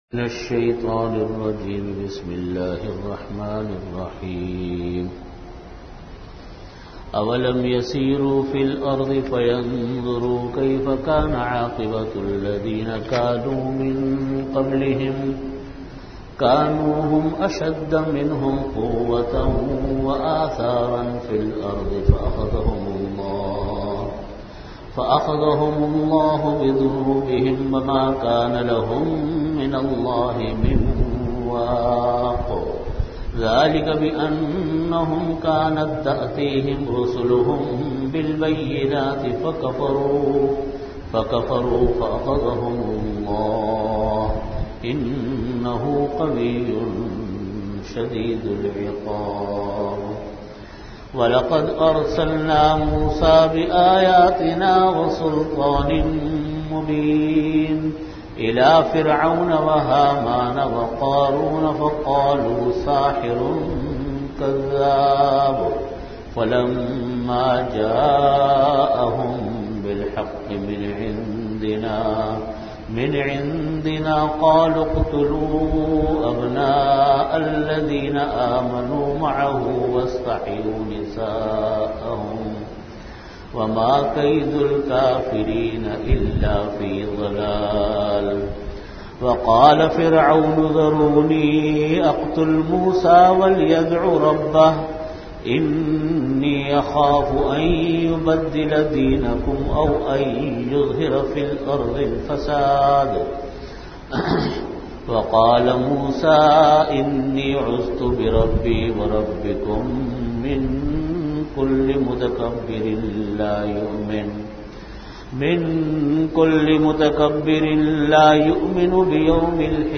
Category: Tafseer
Time: After Asar Prayer Venue: Jamia Masjid Bait-ul-Mukkaram, Karachi